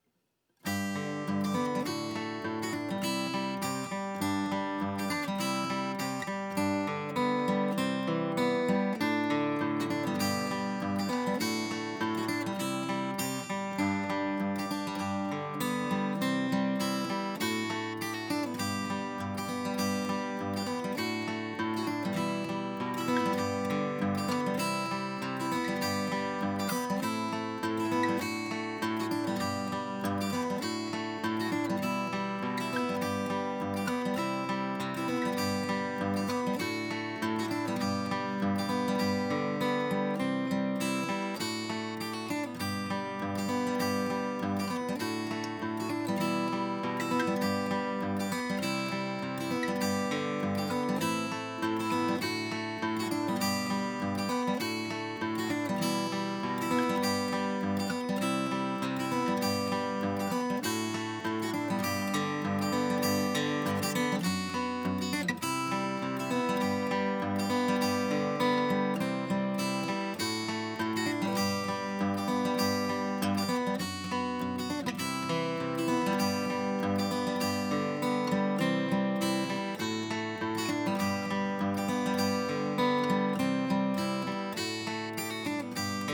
acousticguitar_SSL4KEQ-Ahpf.flac